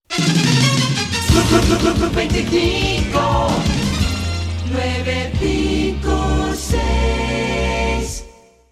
Indicatiu curt de l'emissora